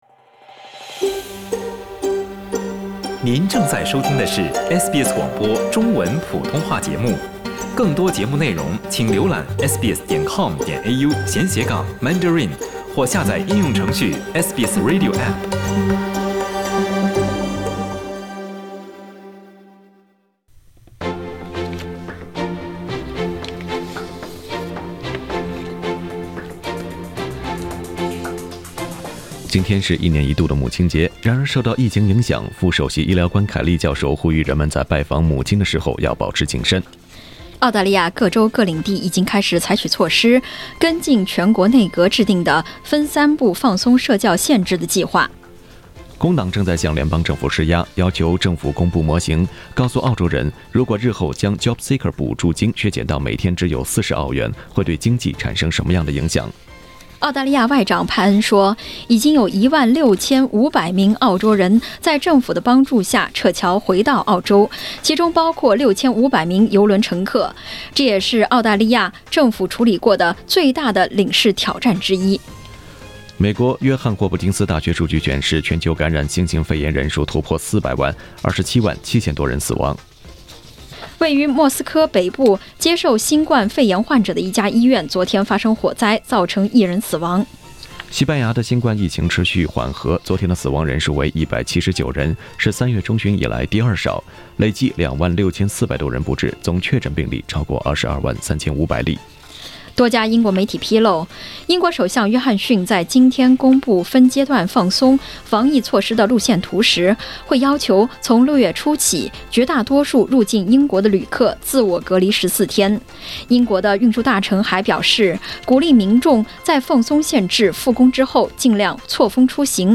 SBS早新闻（5月10日）